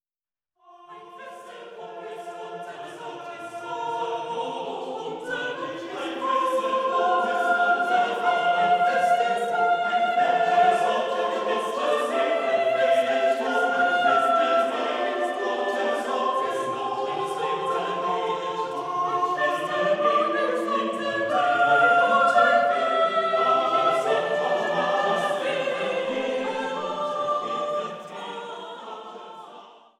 Chorwerke auf Gesänge Martin Luthers